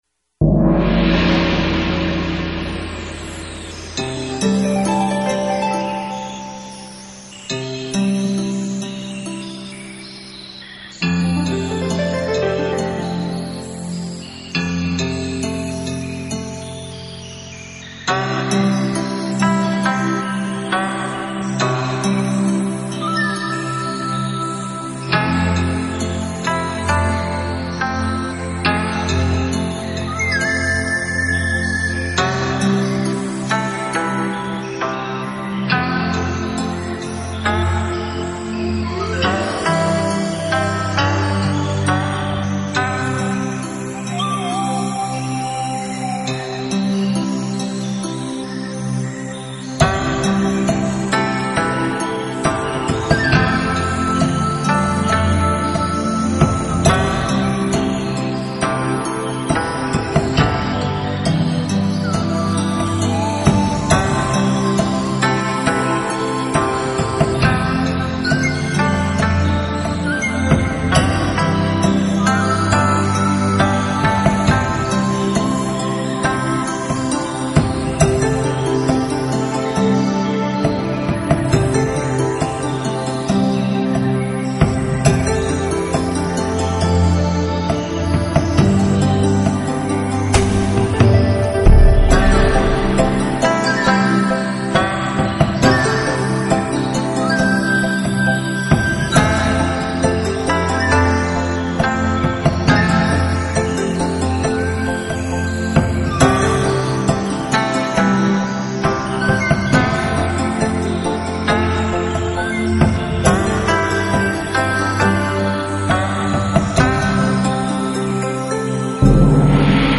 这首歌让人陶醉在优美的音乐旋律里
行云流水，悠然自得。
仿佛梦回烟雨江南，自有一份悠扬宁静在其中。